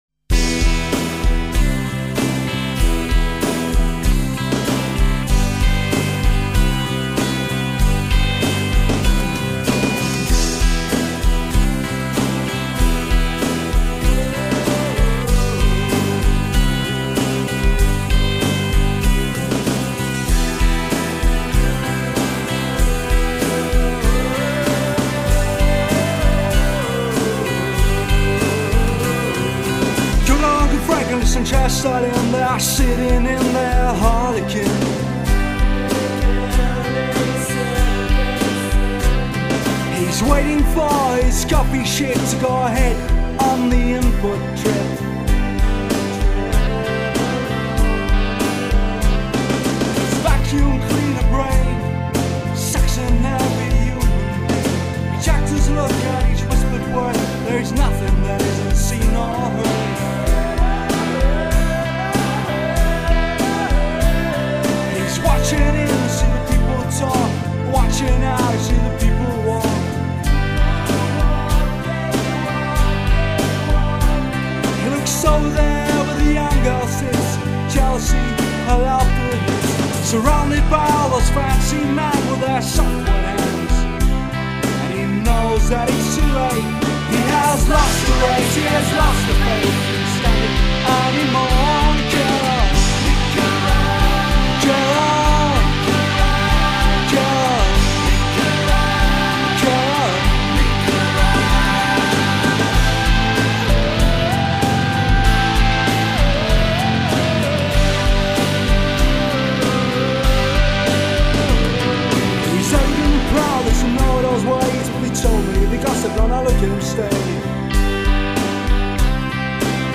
(Alternative Rock)